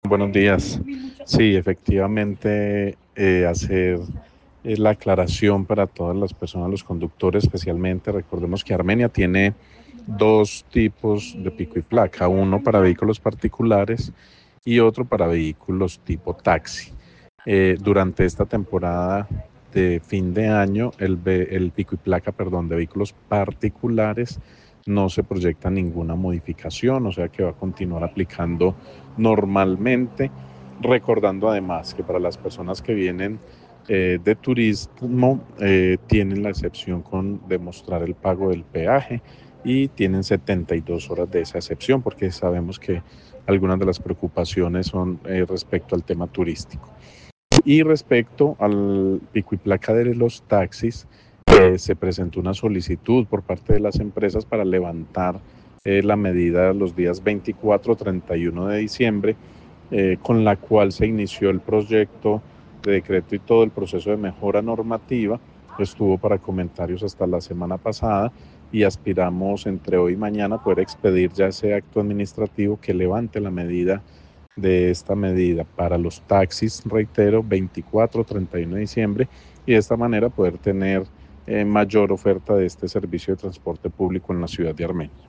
Daniel Jaime Castaño, secretario de tránsito de Armenia